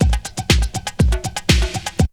ZG2BREAK10#6.wav